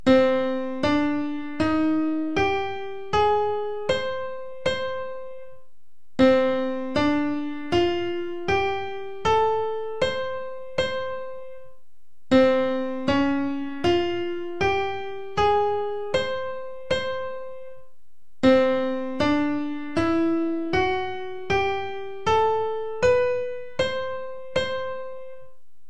The biwa itself is a lutelike instrument, and these musicians sang epic poems often taken from the Tales of the Heike and to support the spirit of samurai.
Japan biwa scales
figure-64a-japanese-scales.mp3